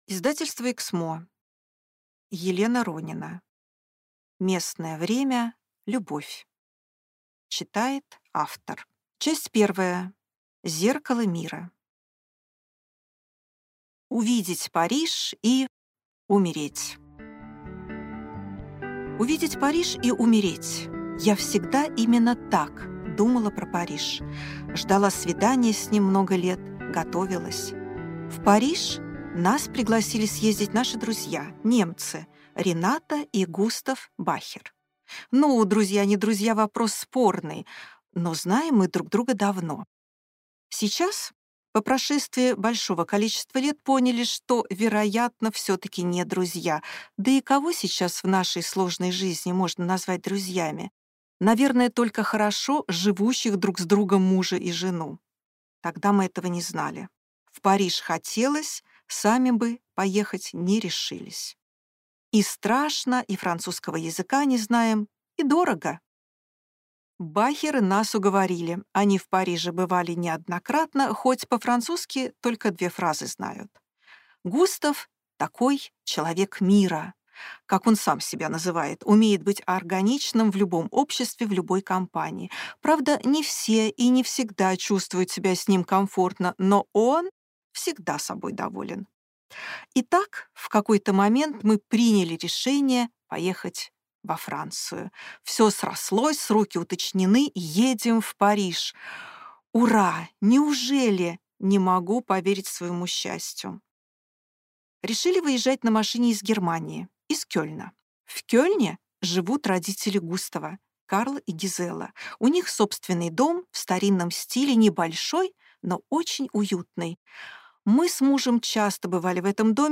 Аудиокнига Местное время – любовь | Библиотека аудиокниг